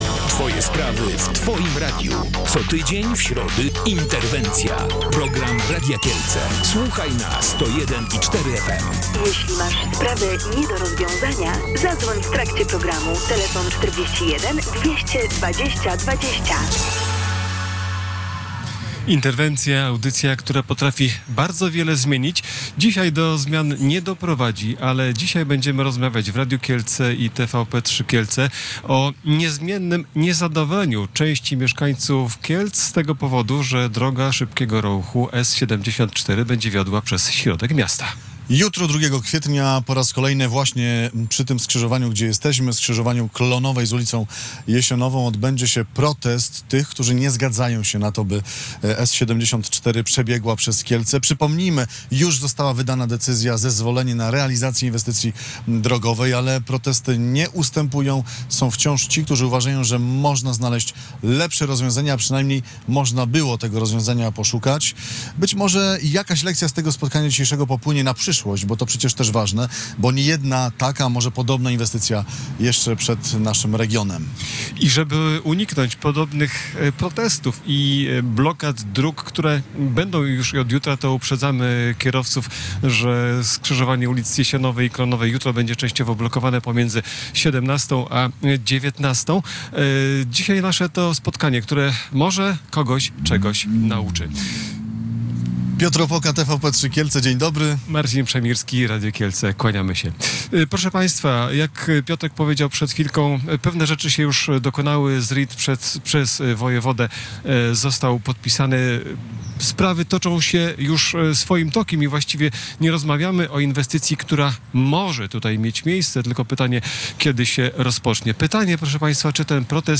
W przeddzień protestu w programie Interwencja powróciliśmy do dyskusji o zasadności prowadzenia drogi ekspresowej przez środek miasta.